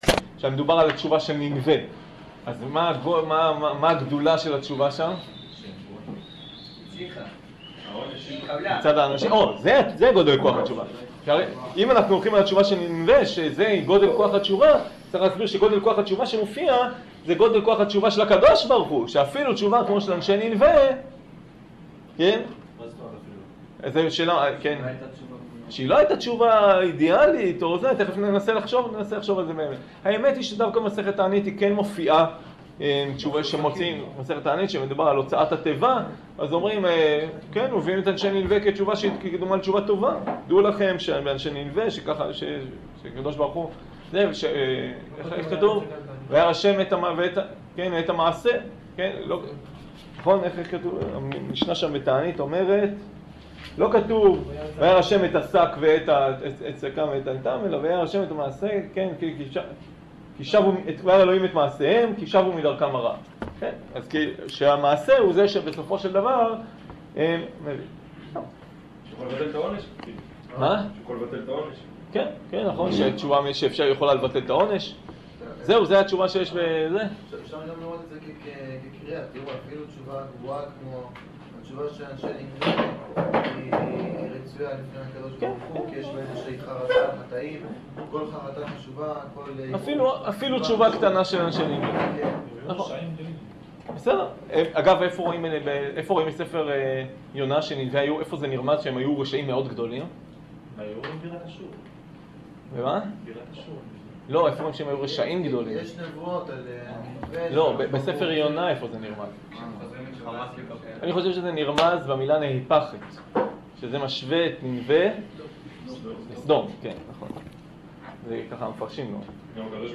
שיעור הכנה ליום כיפור